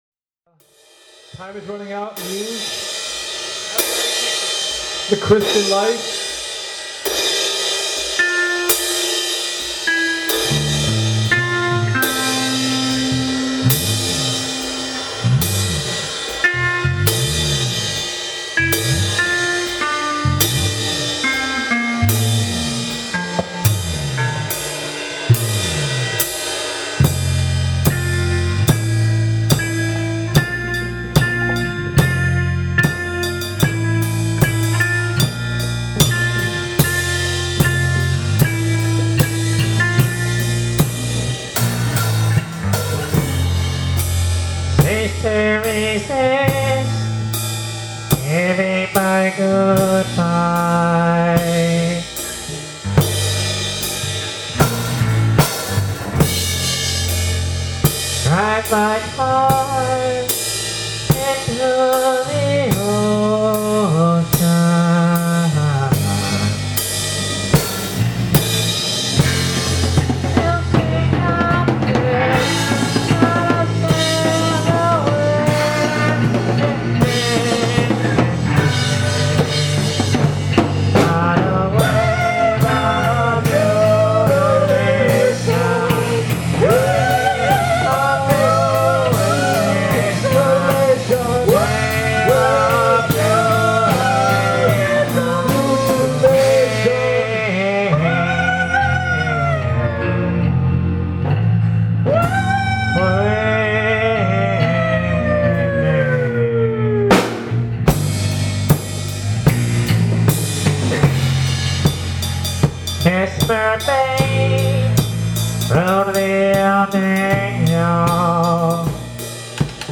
Vox
Drums
Bass
Percussion
Guitar